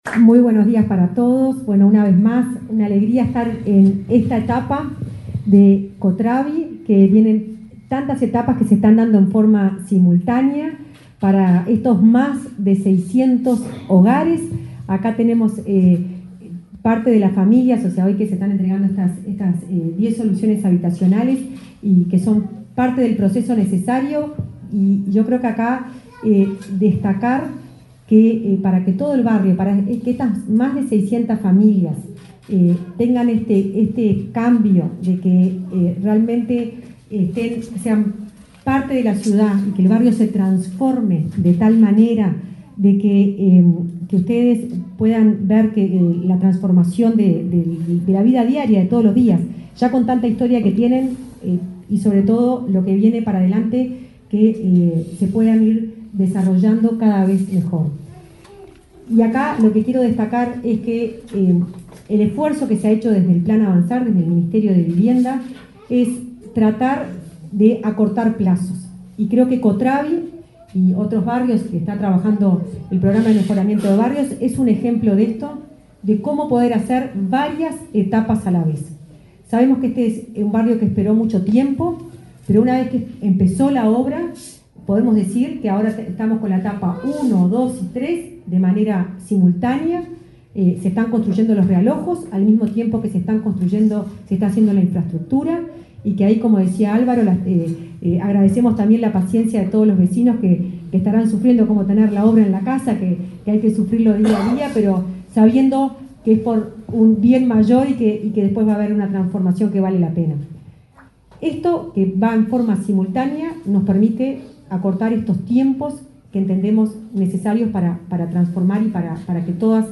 Palabras de autoridades en entrega de viviendas en Montevideo
La directora de Integración Social y Urbana del Ministerio de Vivienda, Florencia Arbeleche, y el titular de sa cartera, Raúl Lozano, participaron,